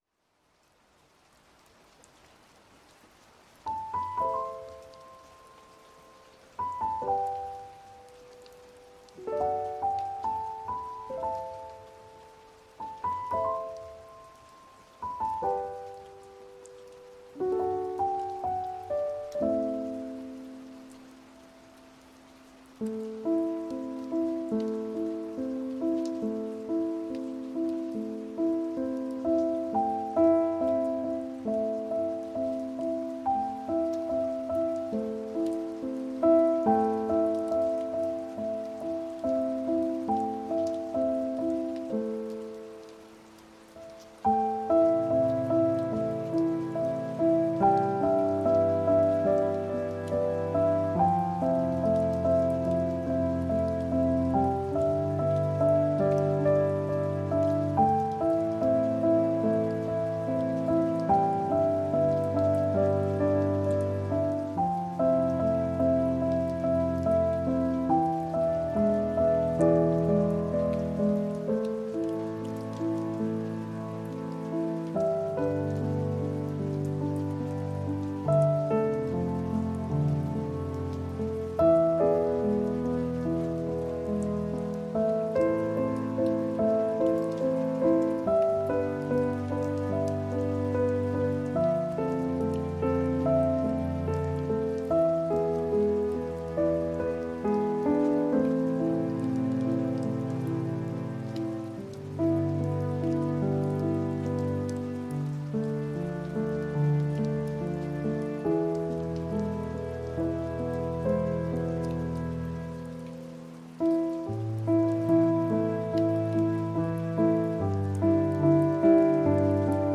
با این موسیقی و ترکیب بارون و پیانو و حس خوب، ذهنتو آروم کن
آخرین خبر/ با این قطعه بی‌کلام و ترکیب بارون و پیانو و حس خوب، ذهنتو آروم کن و بخواب.